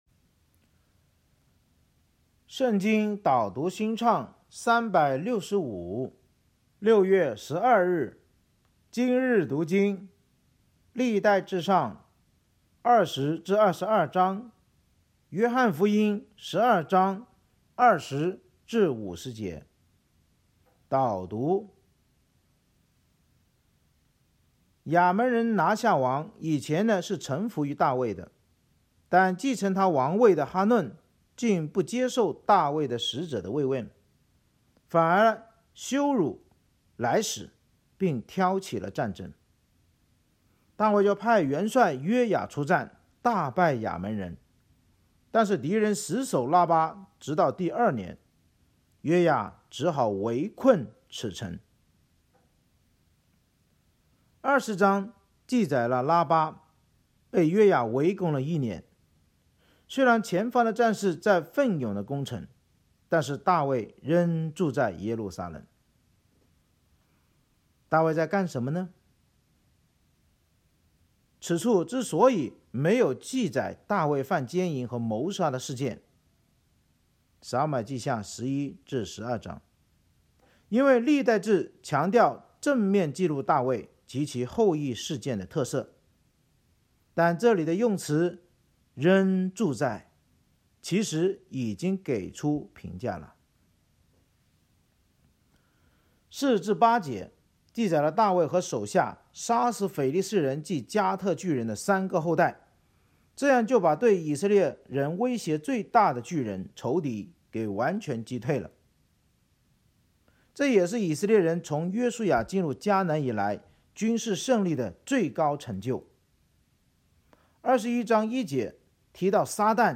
圣经导读&经文朗读 – 06月12日（音频+文字+新歌）